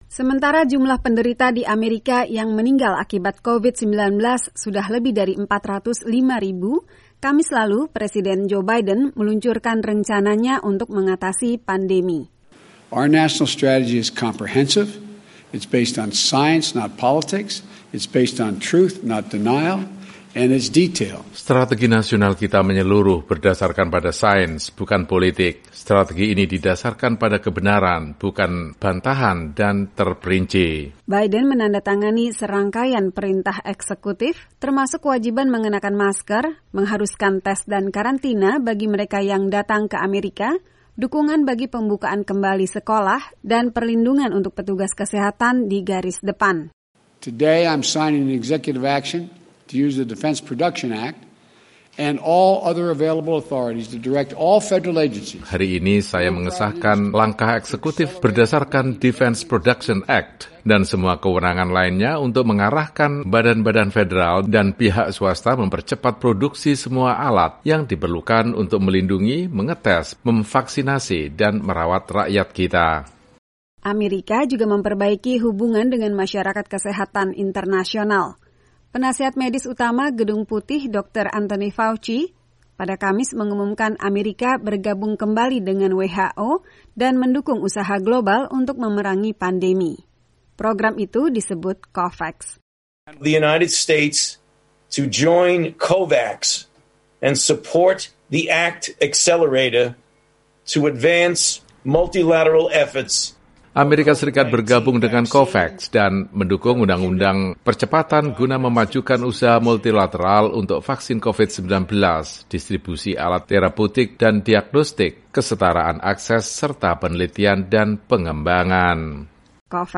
Presiden Biden meluncurkan rencana penanggulangan pandemi nasional dan bergabung kembali dengan WHO. Laporan selengkapnya dari tim VOA.